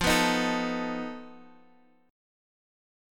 F7b5 chord